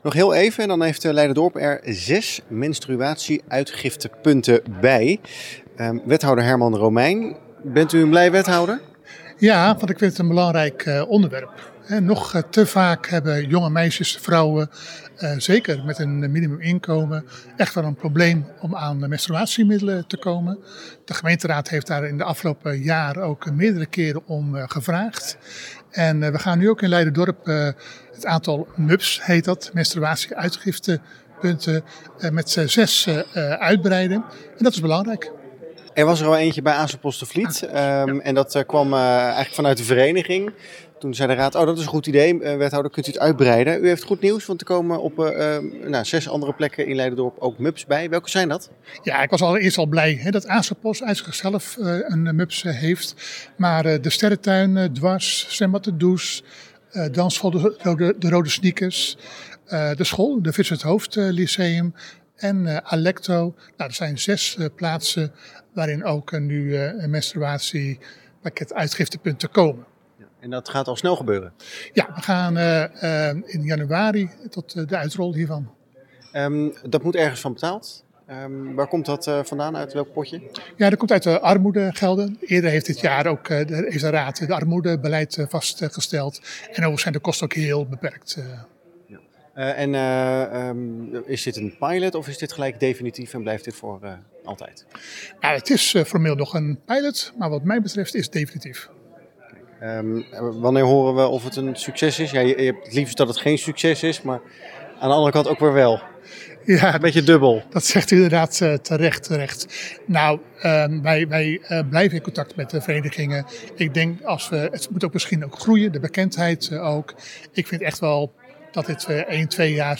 Wethouder Herman Romeijn over de MUPS: